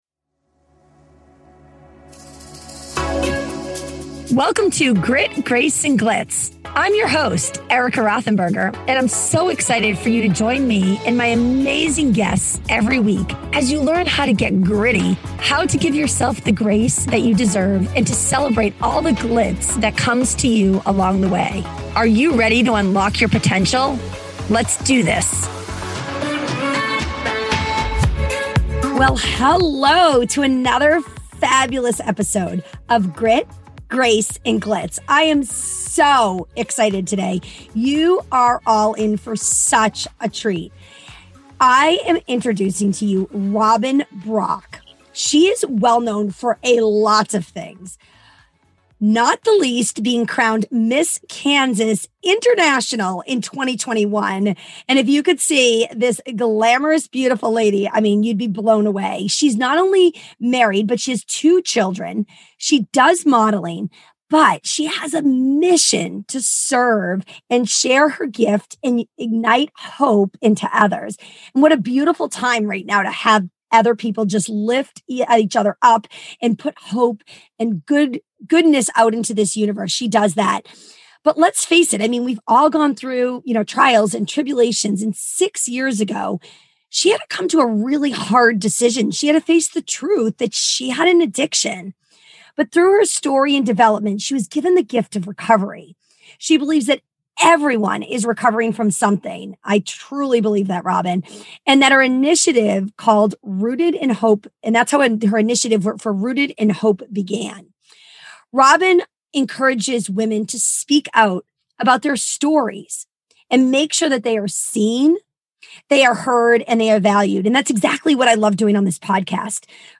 candid conversation